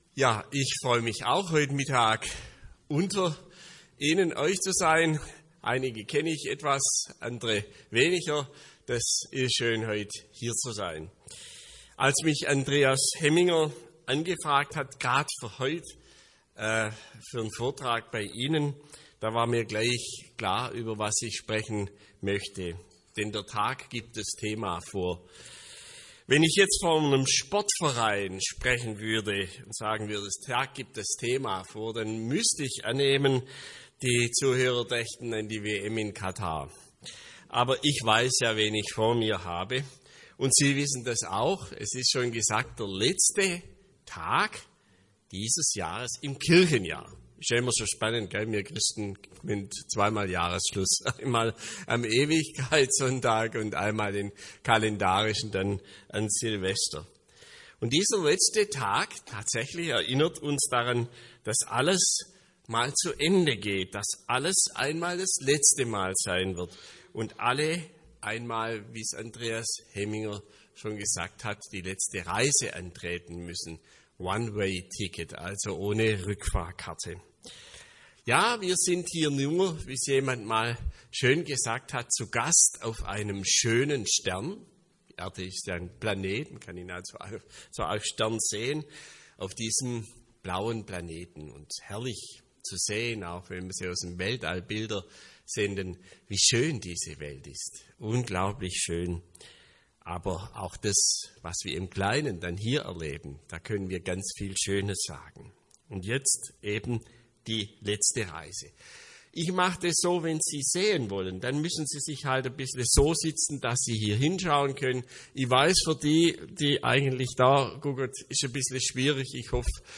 Vortrag als MP3